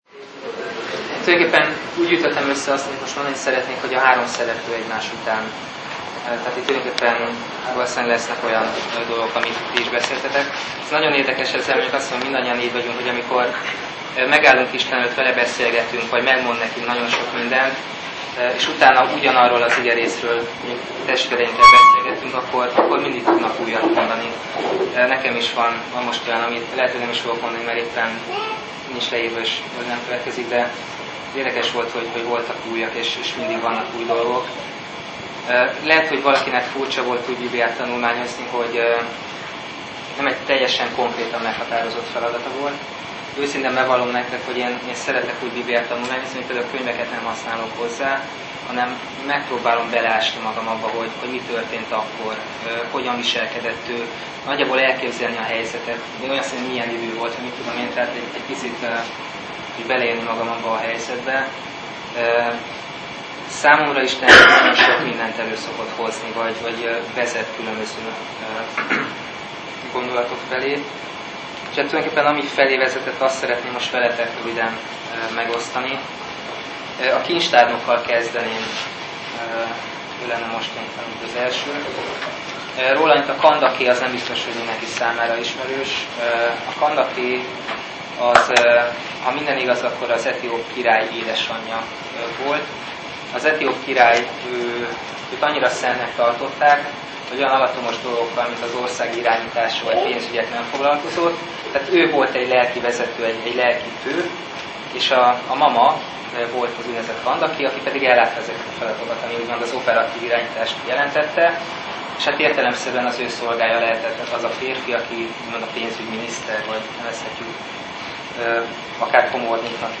Az etióp kincstárnok megtérése - igetanulmányozás a Dánielekkel -